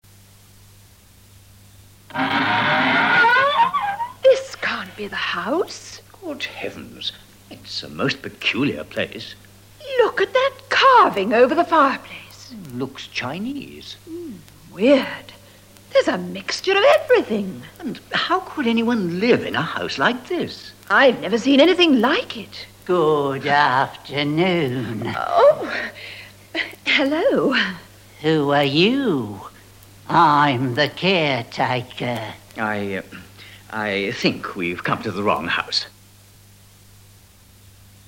Here they are looking at the house for which they have paid a deposit. The mood is SURPRISE.